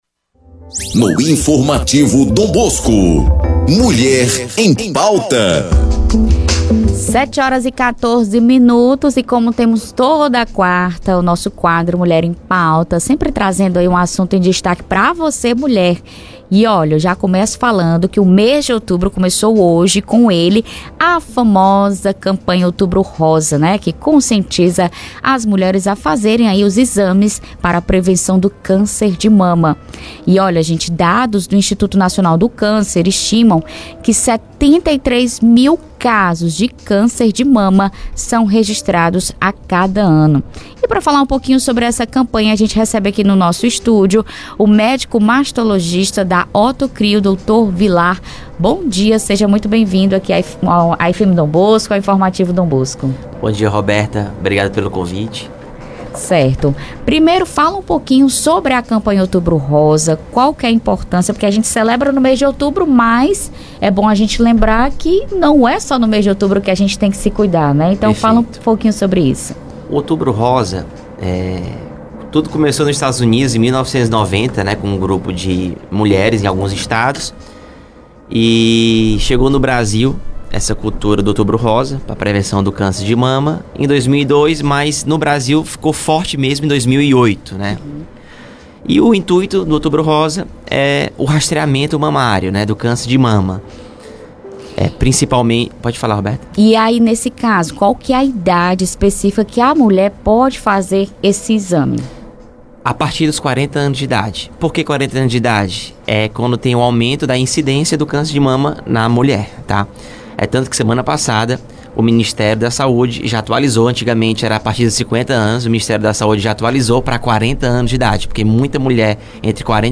Entrevista do dia